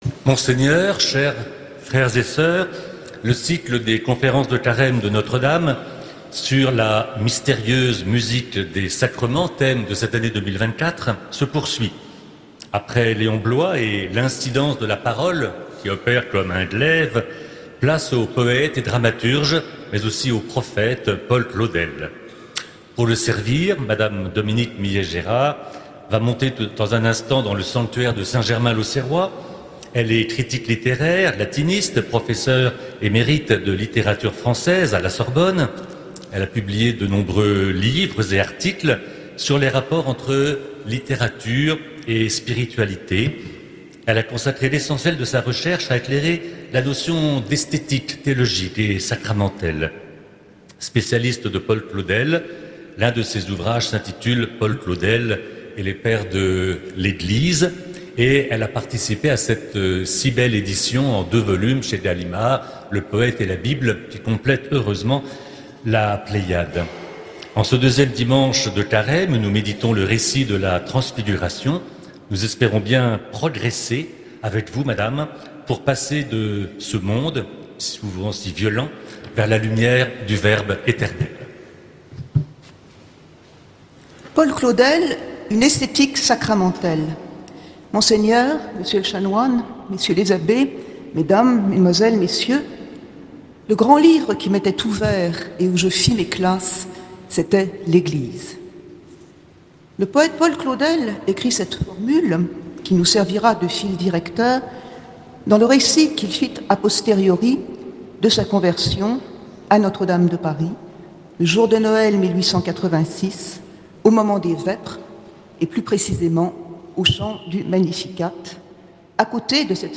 Conférences de carême
Accueil \ Emissions \ Foi \ Carême 2025 \ Conférences de carême De Notre-Dame de Paris.